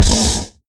骷髅马：受伤
骷髅马在受伤时随机播放这些音效
Minecraft_skeleton_horse_hurt4.mp3